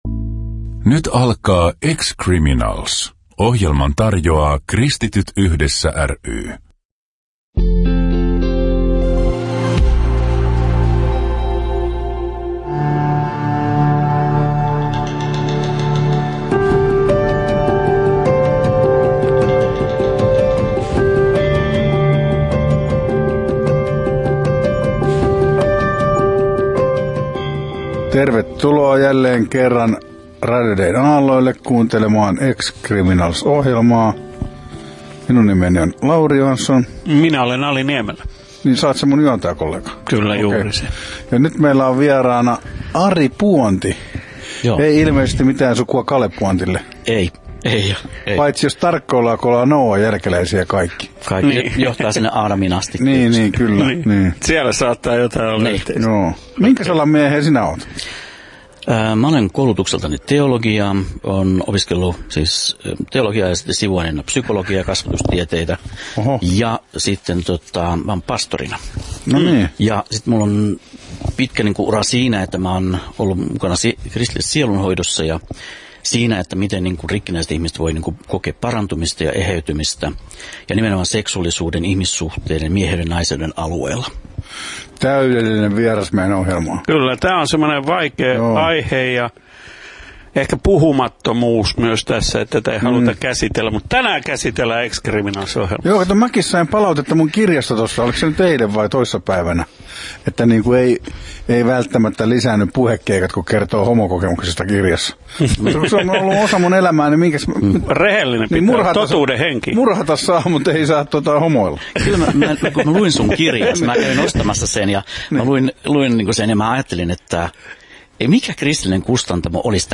Kuuntele koskettava haastattelu: Osa I: